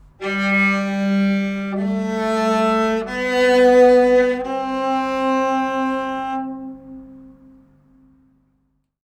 Sounds on strings in Baritone Qeychak are like this: